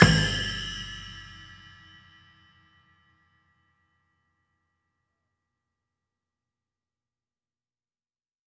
Snare, Splash, Kick OS.wav